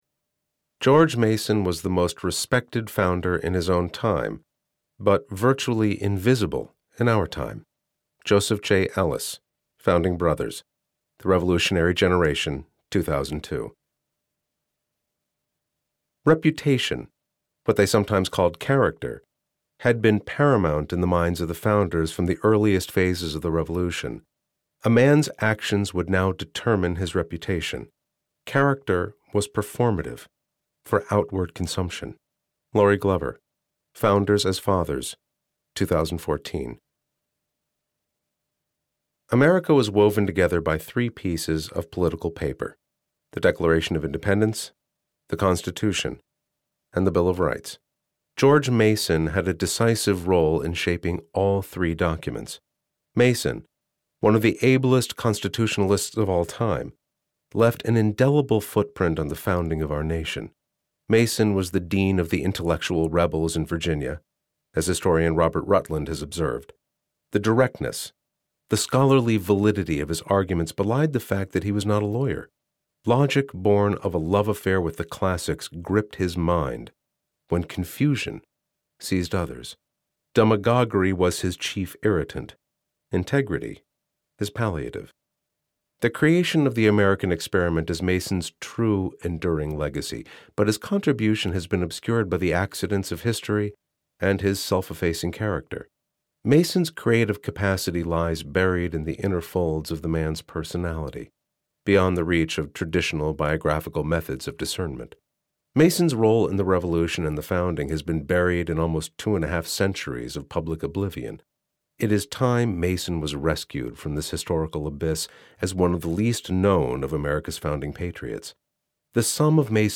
George Mason - The Founding Father Who Gave Us the Bill of Rights - Vibrance Press Audiobooks - Vibrance Press Audiobooks